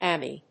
/ˈæmi(米国英語), ˈæmi:(英国英語)/